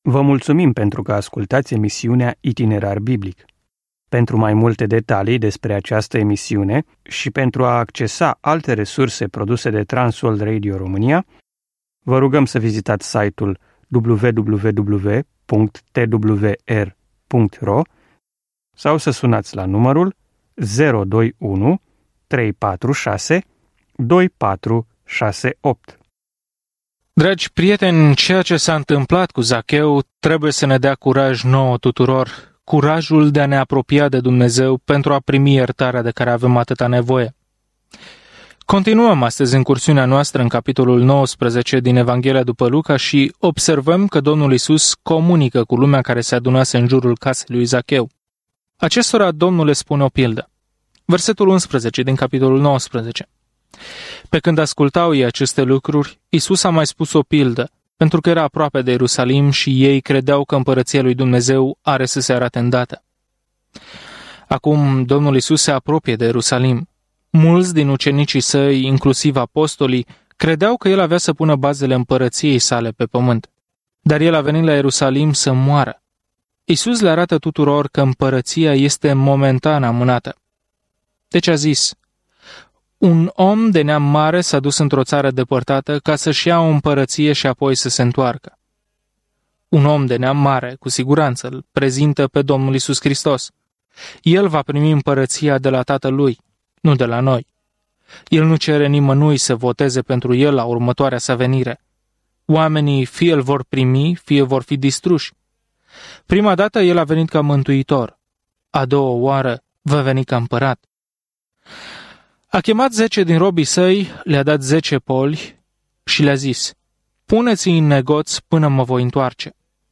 Scriptura Luca 19:11-48 Luca 20:1-26 Ziua 23 Începe acest plan Ziua 25 Despre acest plan Martorii oculari informează vestea bună pe care o spune Luca despre povestea lui Isus de la naștere până la moarte și până la înviere; Luca reamintește și învățăturile Sale care au schimbat lumea. Călătoriți zilnic prin Luca în timp ce ascultați studiul audio și citiți versete selectate din Cuvântul lui Dumnezeu.